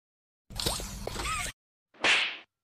Grabpack Slap Sound Effect Download: Instant Soundboard Button